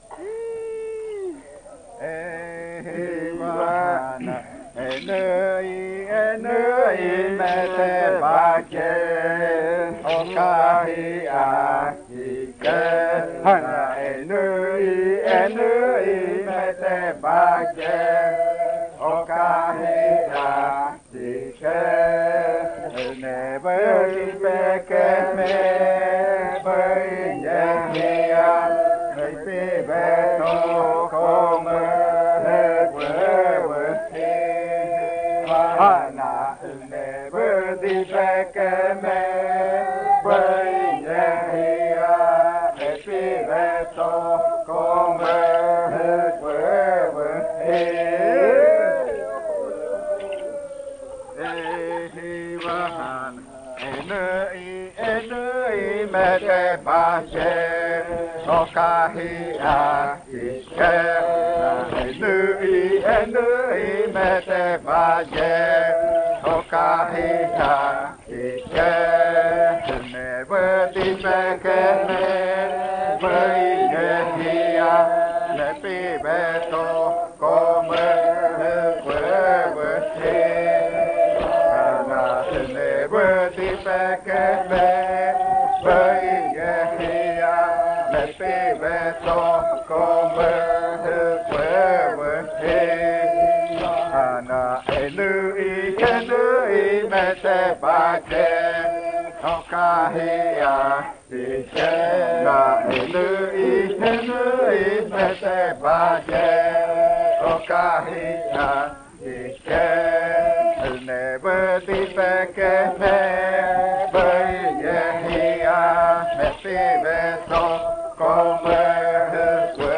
26. Baile de nombramiento. Canto n°22
Puerto Remanso del Tigre, departamento de Amazonas, Colombia